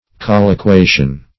colliquation - definition of colliquation - synonyms, pronunciation, spelling from Free Dictionary
Colliquation \Col`li*qua"tion\, n.